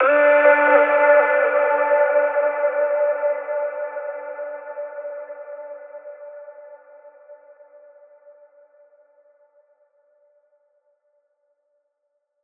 VR_vox_hit_calling_Dmin.wav